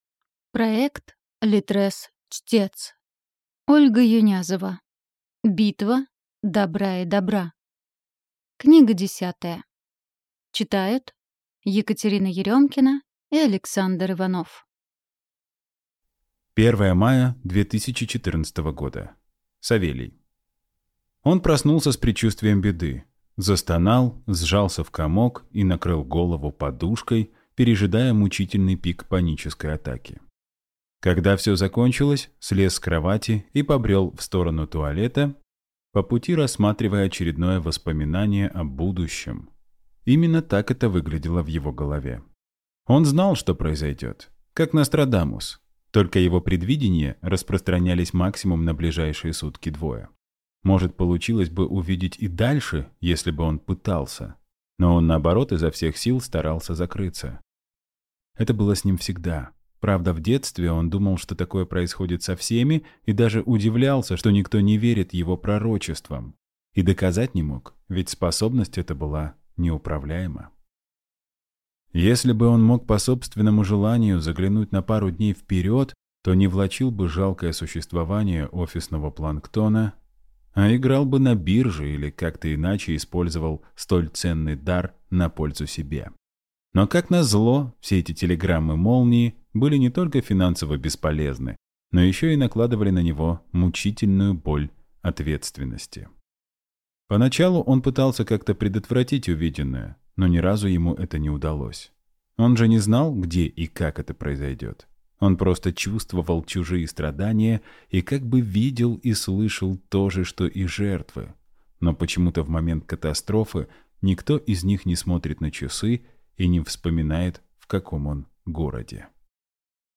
Аудиокнига Битва добра и добра | Библиотека аудиокниг
Прослушать и бесплатно скачать фрагмент аудиокниги